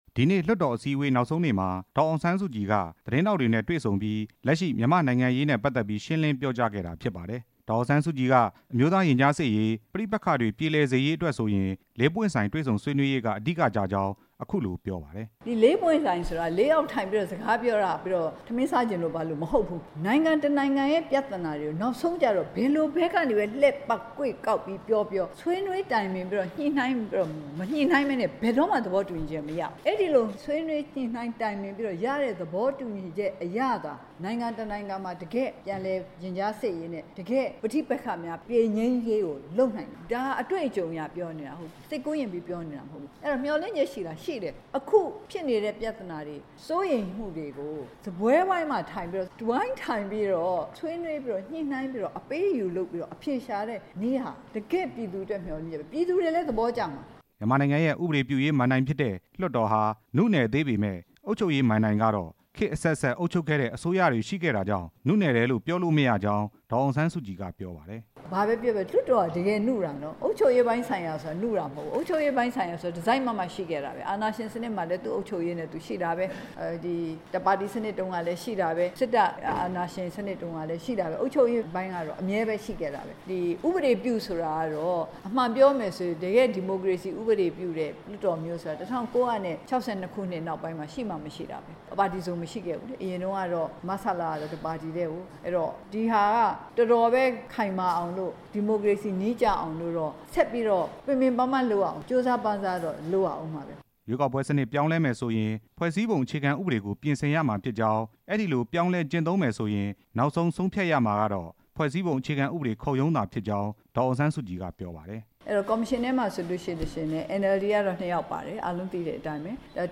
ဒေါ်အောင်ဆန်းစုကြည် သတင်းစာရှင်းလင်းပွဲ